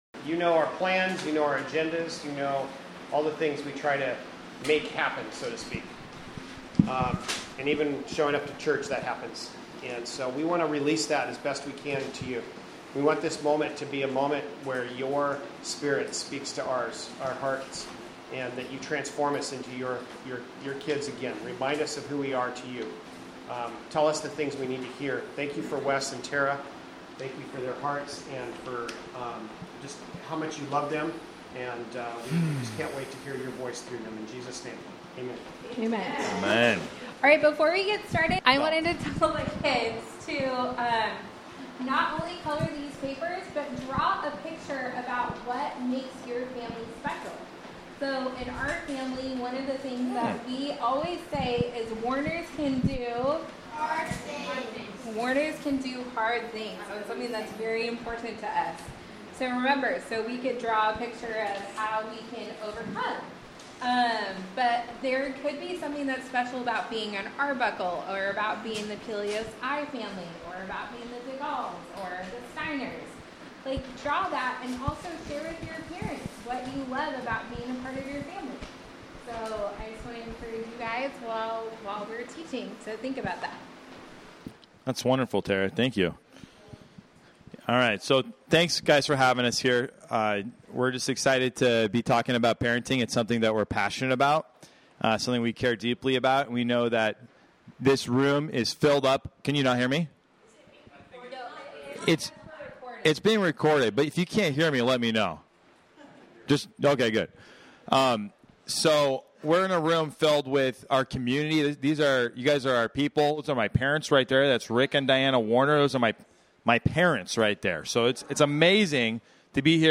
Passage: John 14:18-20, John 14:9, Romans 12:2, Proverbs 17:9 Service Type: Sunday Morning Related « Yes and Amen to Marriage!